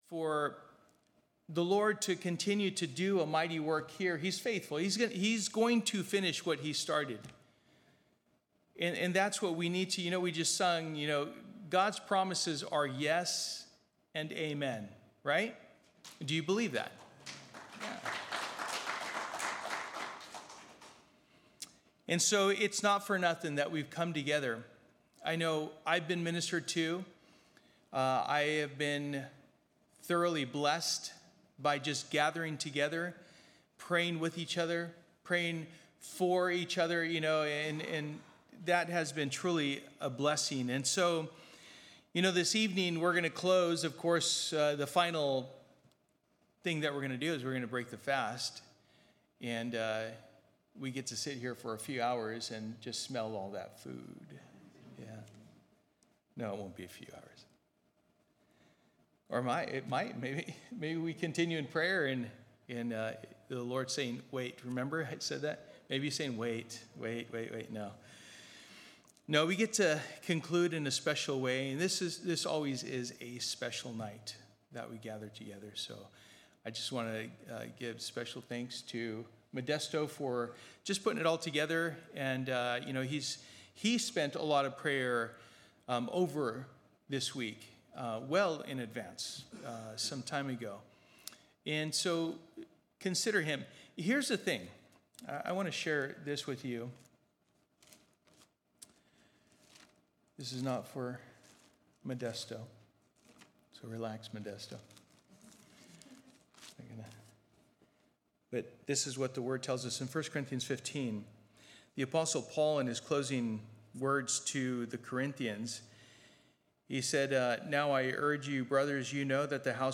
Passage: Colossians 3:12-17 Service: Special Event